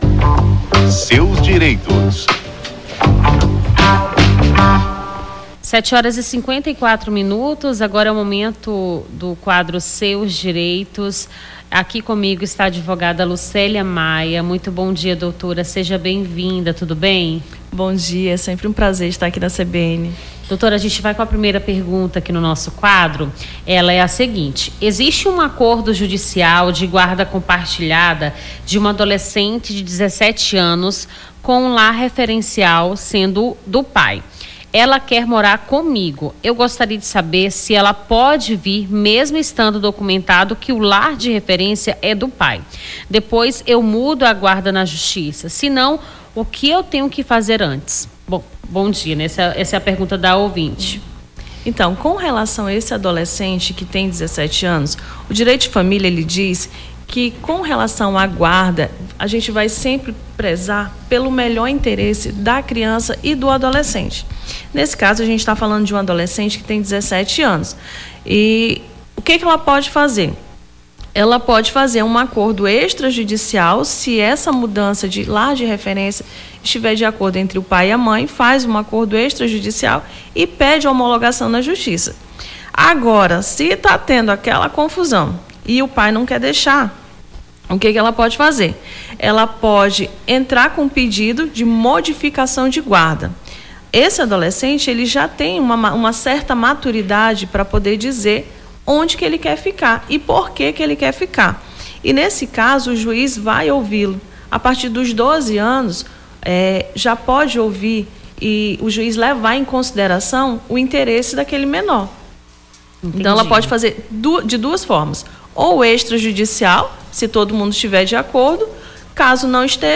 Seus Direitos: advogada esclarece dúvidas dos ouvintes sobre direito de família